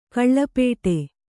♪ kaḷḷapēṭe